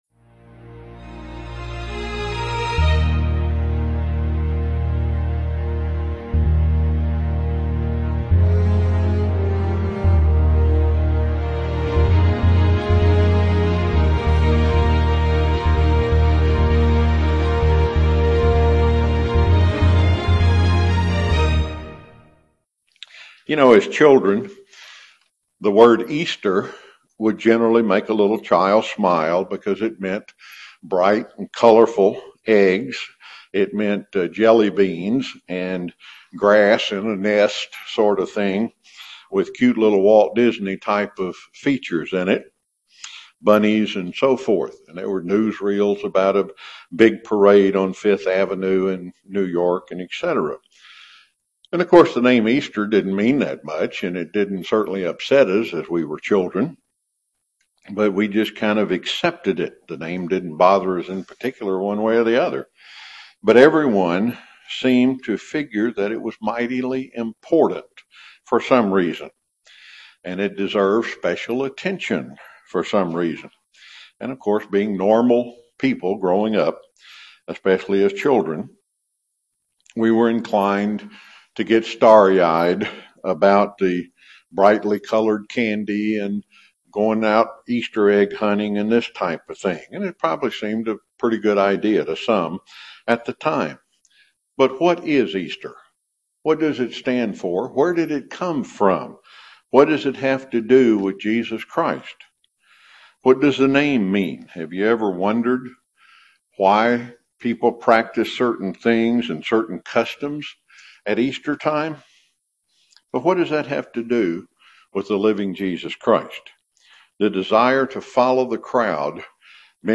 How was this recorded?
Given in Chattanooga, TN